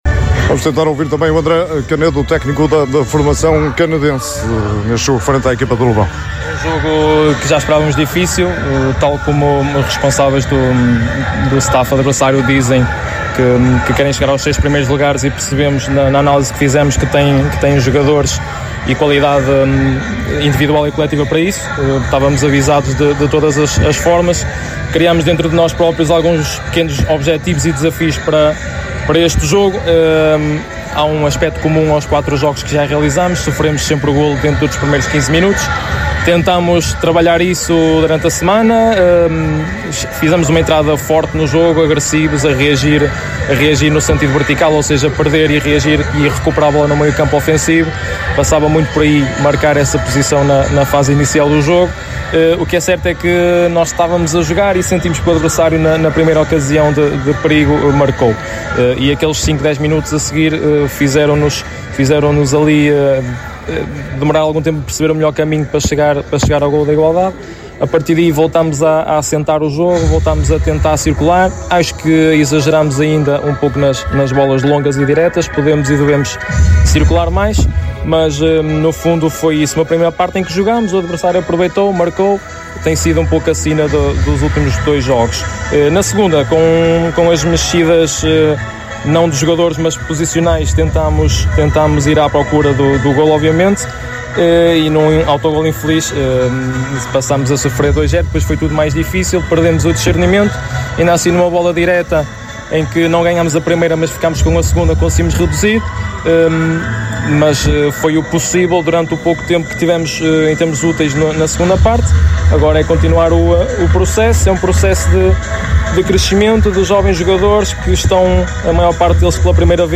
ouviu os protagonistas da partida no final.